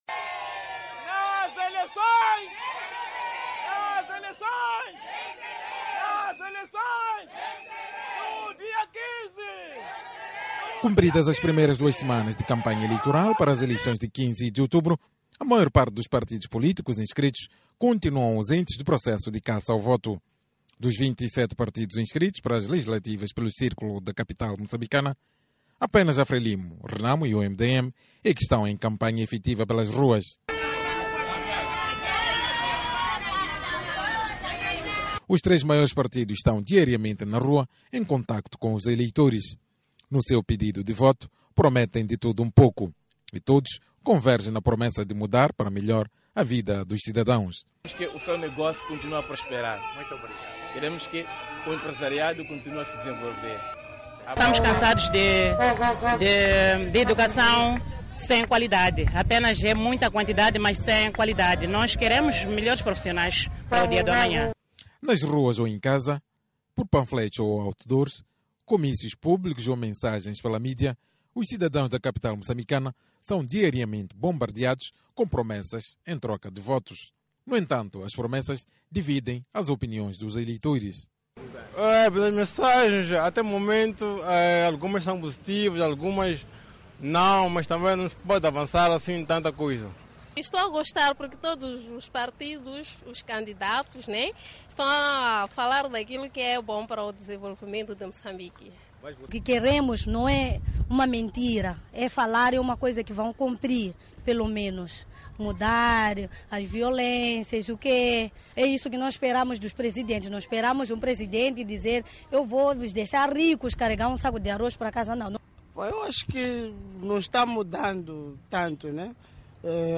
Eleitores moçambicanos falam à Voz da América